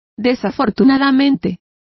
Complete with pronunciation of the translation of unfortunately.